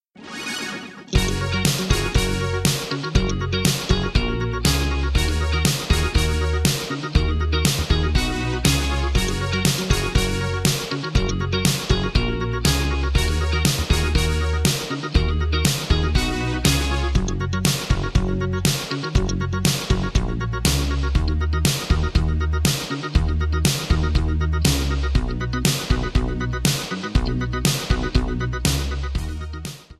MPEG 1 Layer 3 (Stereo)
Backing track Karaoke
Pop, 1980s